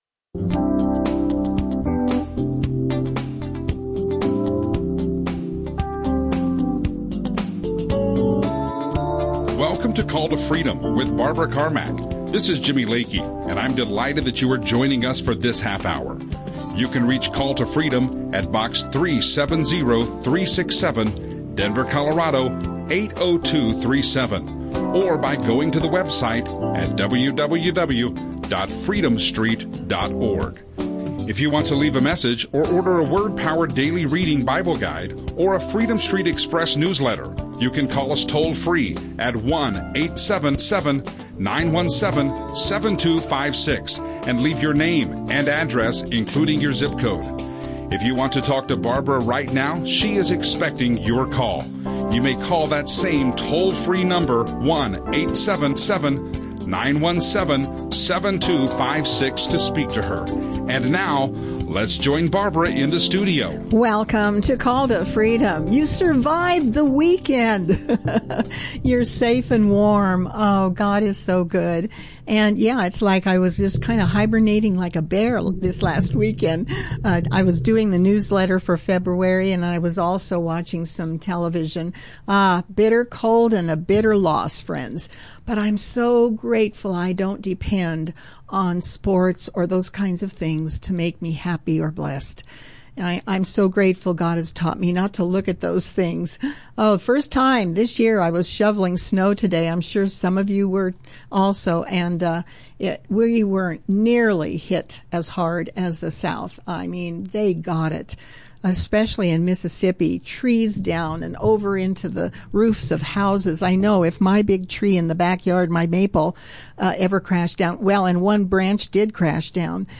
Audio teachings